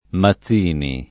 maZZ&ni o anche mazz&ni secondo i casi] cogn. — due cogn. distinti in origine: uno più comune, der. come vari altri da mazza e pronunziato soltanto con -z- sorda; e un altro, ligure, illustrato da Giuseppe M. (1805-72), der. da una voce dial. equivalente a maggio e pronunziato propr. con -z- sonora: uso però circoscritto agli abitanti della Liguria (con l’aggiunta, per fedeltà ideale, di pochi mazziniani), essendo generale nel resto d’Italia, già dall’800, l’assimilaz. alla pronunzia (con -z- sorda) dell’altro cognome